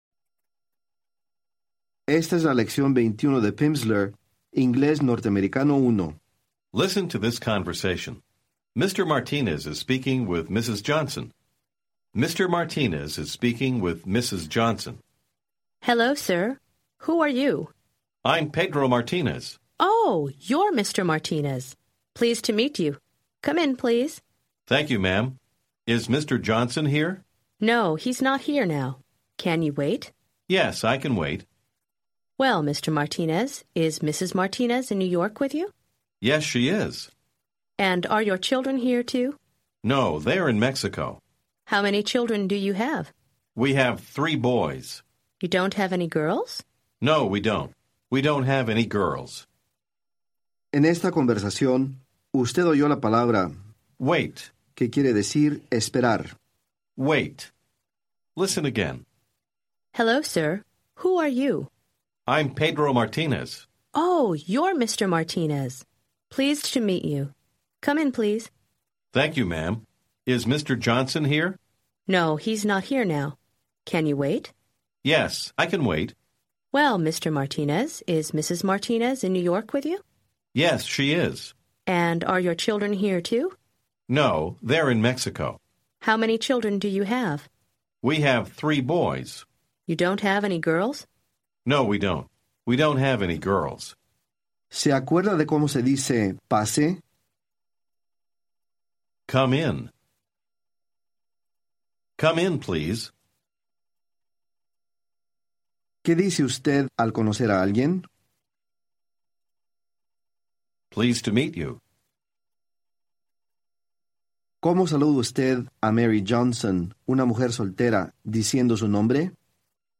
Audiobook
English for Spanish Speakers Phase 1, Units 21-25 build on material taught in prior units. Each lesson provides 30 minutes of spoken language practice, with an introductory conversation, and new vocabulary and structures.